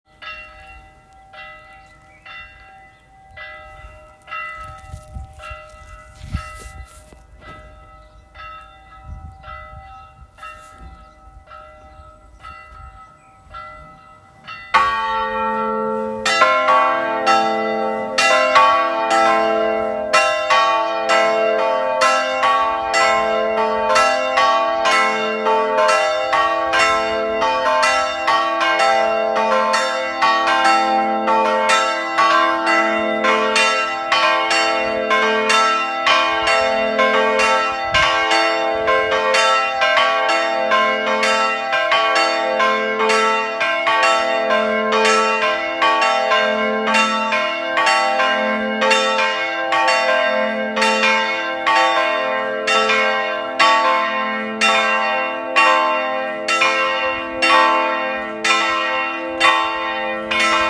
Ze wegen 485 kg., 285 kg. en 140 kg. en hebben de toonsoorten A, C en E. Het geluid van de klokken is indertijd afgestemd op de toonsoort van de kerkklok van de Dorpskerk. In het geluidsfragment hieronder hoort u eerst de klok van de Dorpskerk en daarna beginnen de 3 klokken van de Kruiskerk te luiden.
Geluidsopname-klokken.m4a